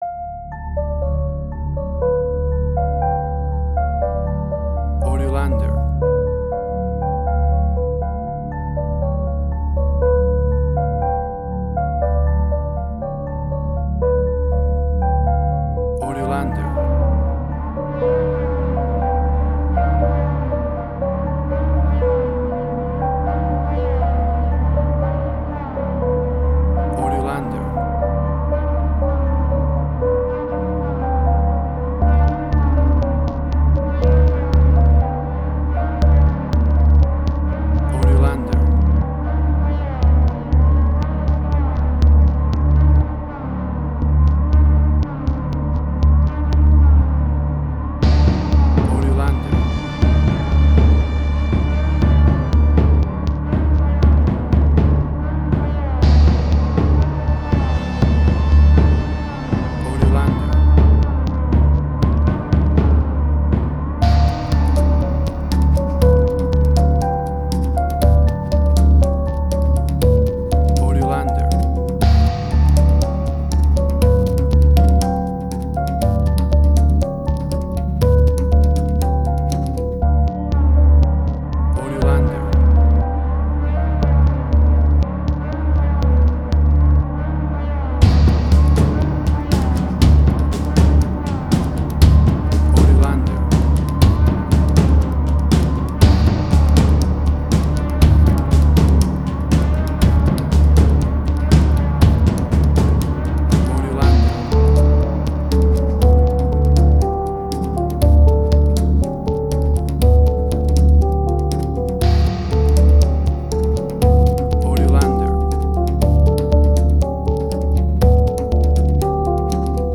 Suspense, Drama, Quirky, Emotional.
WAV Sample Rate: 16-Bit stereo, 44.1 kHz
Tempo (BPM): 120